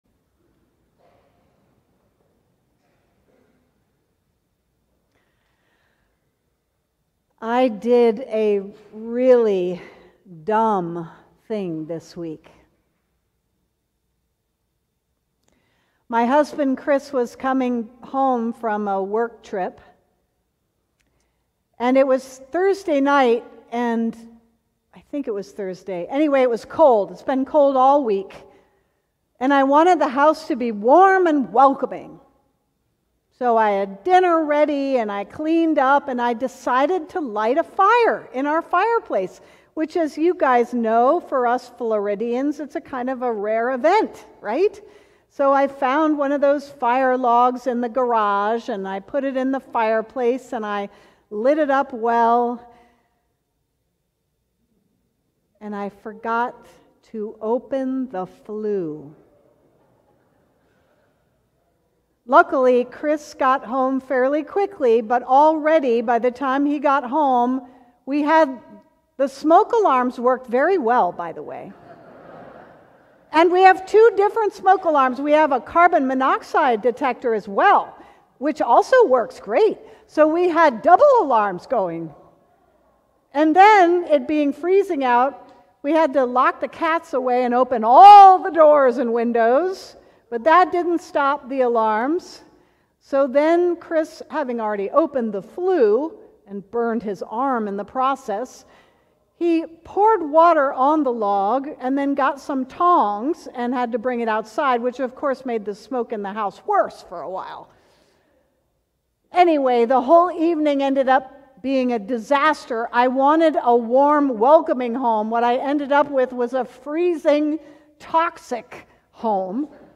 Sermon: Keys to Wisdom - St. John's Cathedral